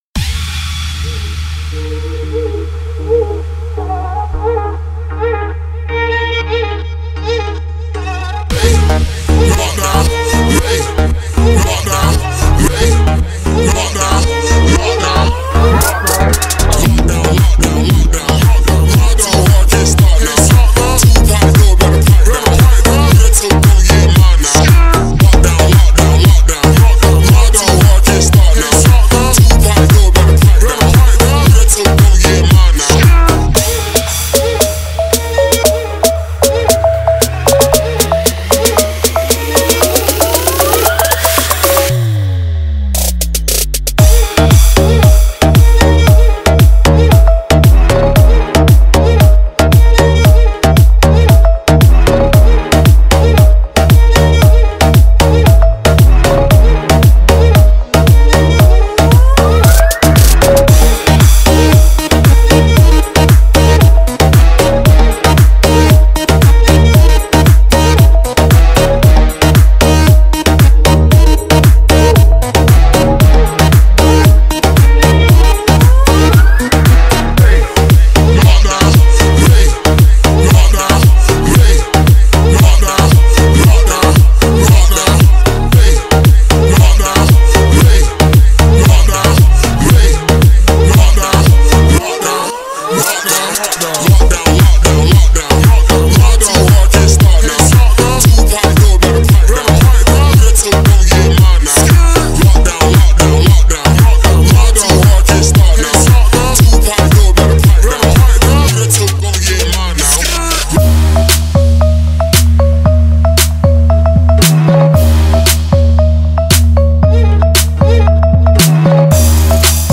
ریمیکس
Download music for shuffle dance